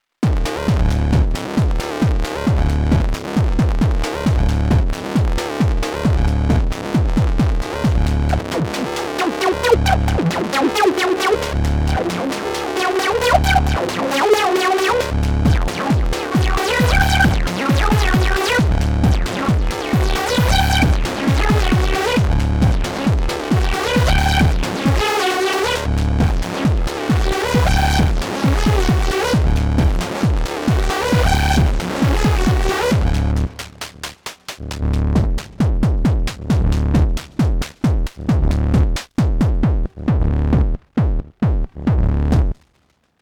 and this was later after improvising with it:
OSC 1 = noises and bass, OSC 2 = bassline/lead, OSC 3 = kick.
In all cases, one latched note (no arp, no sequencer).